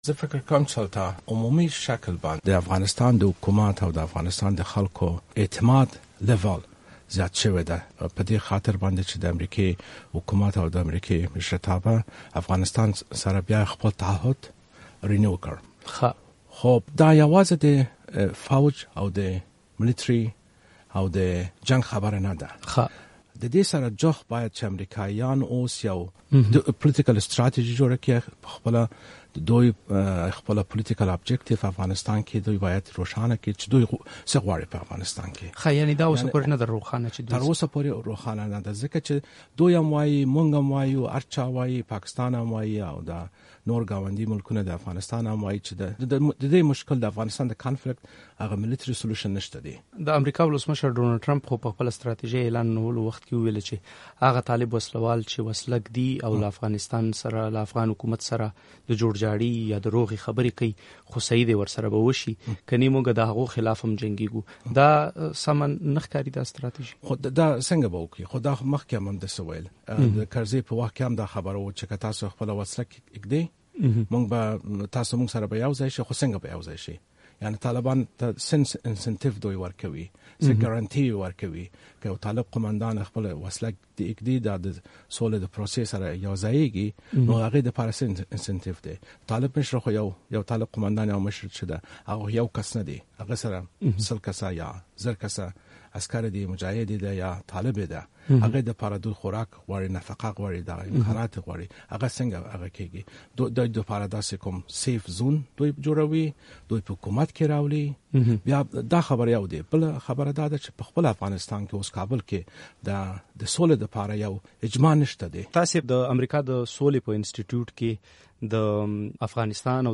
مرکې، شننې، تبصرې